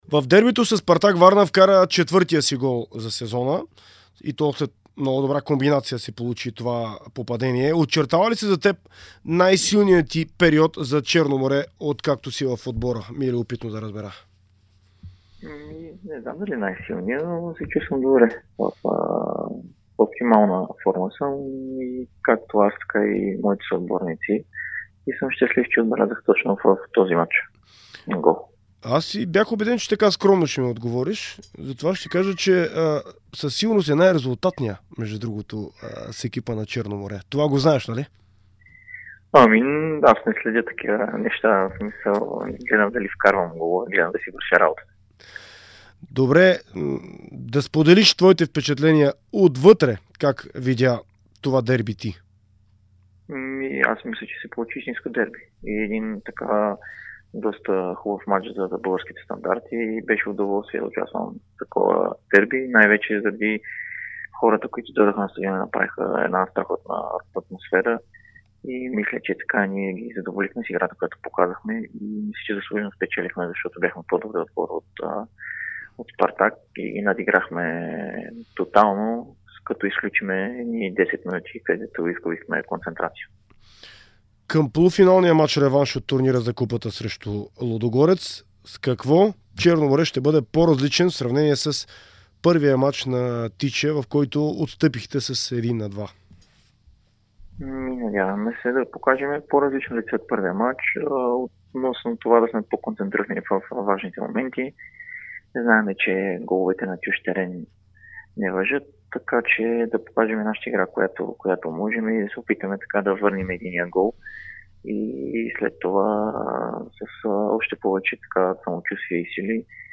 В интервю за Дарик радио и dsport той сподели впечатленията си от изминалото дерби, както и очакванията си за предстоящата среща реванш от турнира за купата на България срещу Лудогорец.